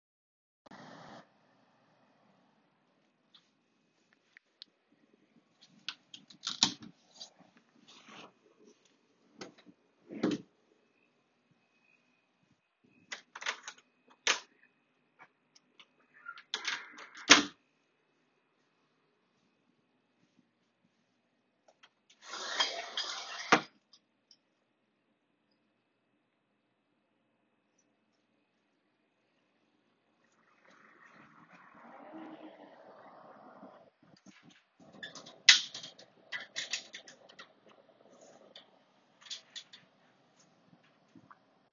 Field Recording
tapping my nails on computer, typing on computer, heater hum